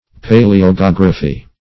paleogeography - definition of paleogeography - synonyms, pronunciation, spelling from Free Dictionary